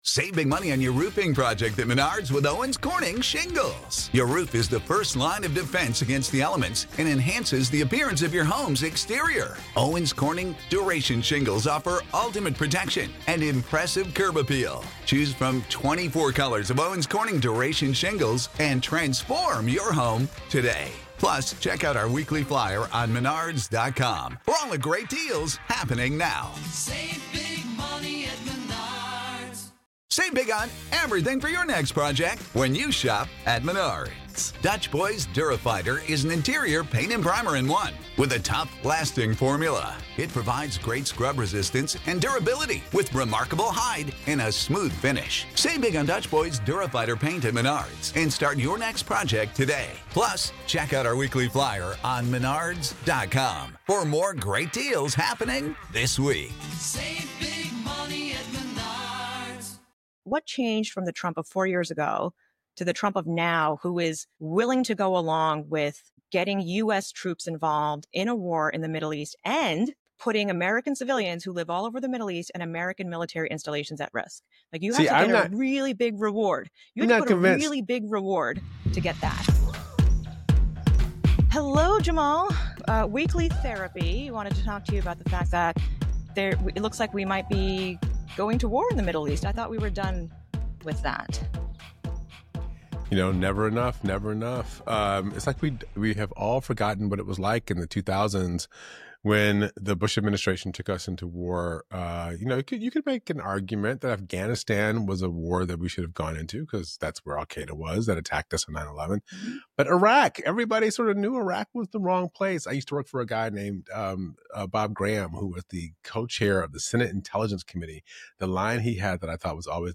Episode 41, Jun 18, 10:00 PM Headliner Embed Embed code See more options Share Facebook X Subscribe Nayyera Haq & Jamal Simmons discuss the potential for war in the Middle East, particularly focusing on the United States' involvement in Iran and Israel's actions. They explore the implications of American foreign policy, the perspectives of younger generations on these issues, and the reactions of global powers. The conversation highlights the complexities and challenges of navigating international relations in a rapidly changing geopolitical landscape.'